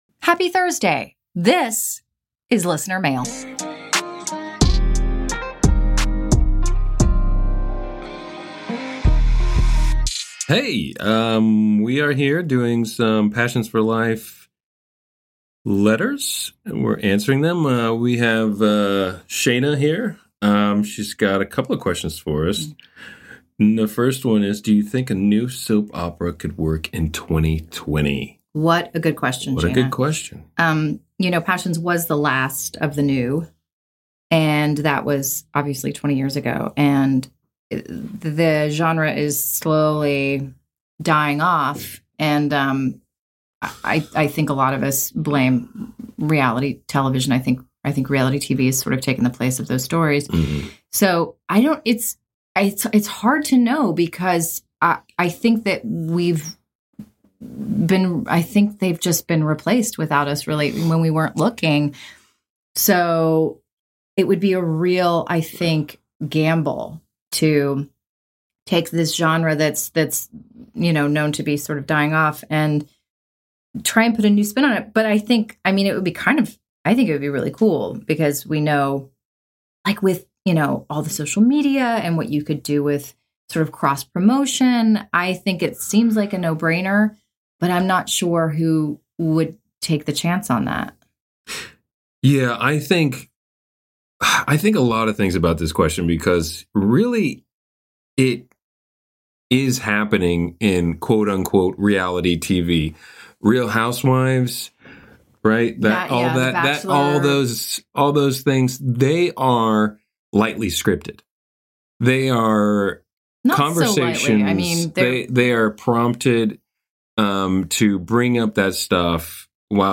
Mail from listeners that we read out loud and then respond to.